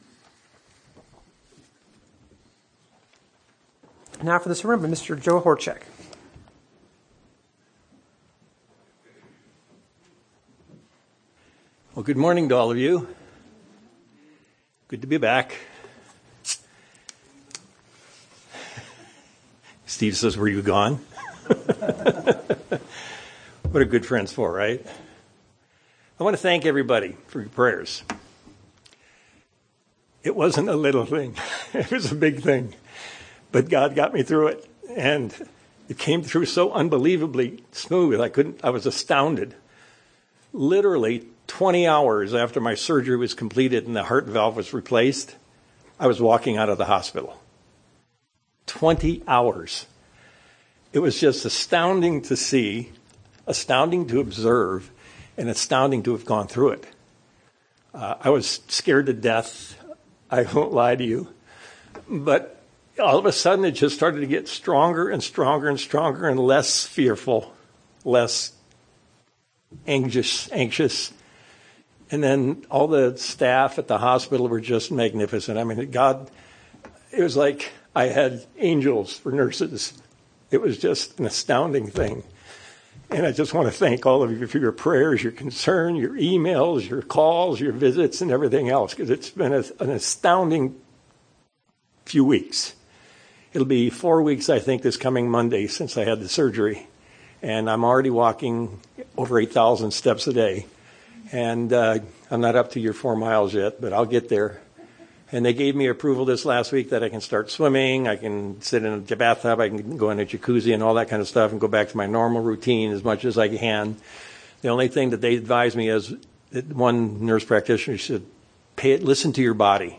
As we'll see in this sermon, the Apostle Paul was constantly evaluating his performance as a Christian. He realized that he faced, as we all do, a constant struggle within his being: the struggle to do what is right and not do what is bad or evil.